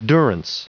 Prononciation du mot durance en anglais (fichier audio)
Prononciation du mot : durance